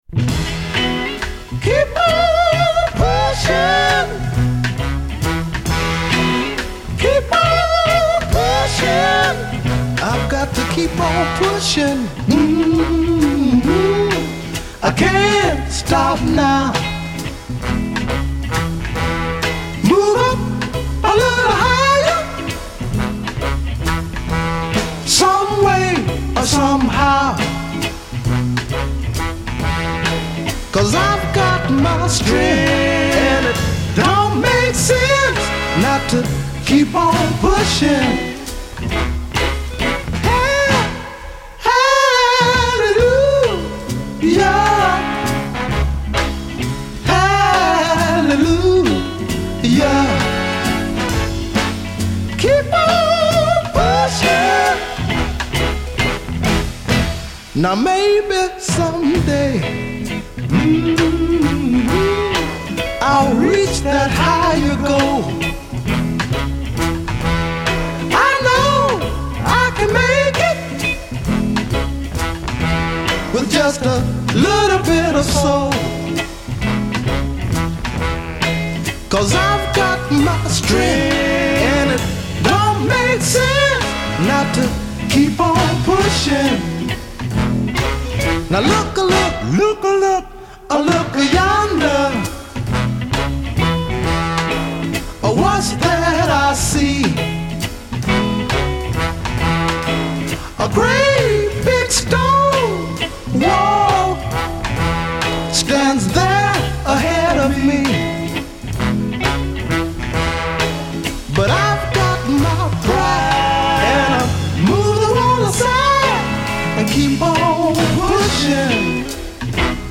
Category: Song of the Day, Soul